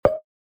scrollMenu.mp3